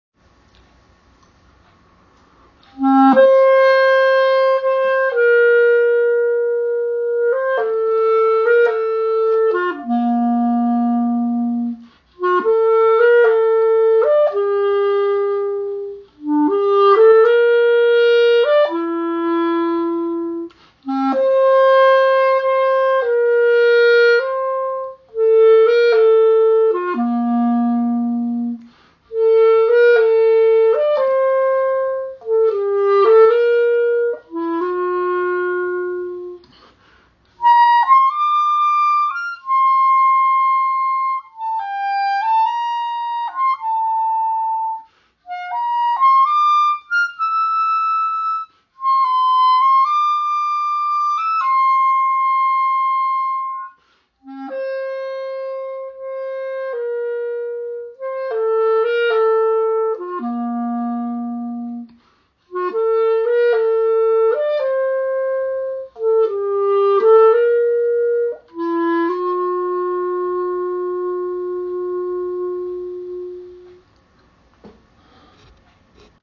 without the lush backup